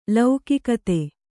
♪ laukikate